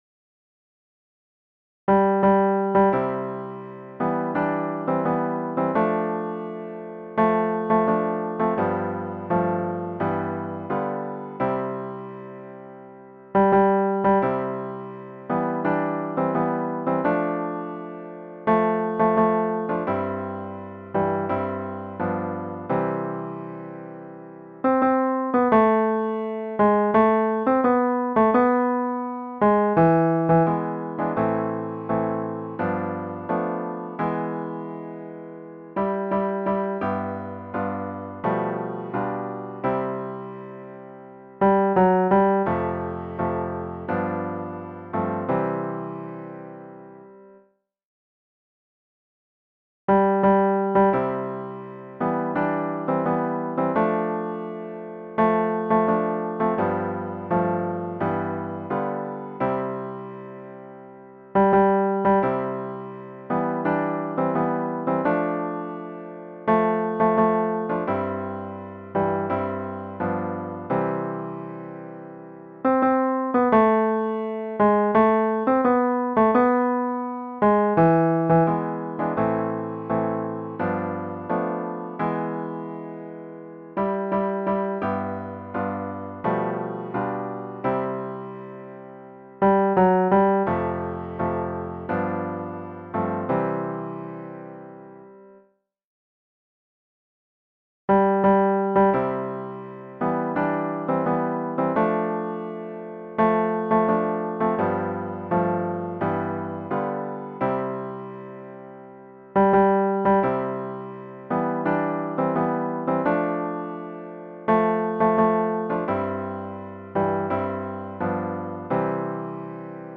KRISTIANSANDS HAANDVERKER SANGFORENING
Kristiansands-sangen-4st.mp3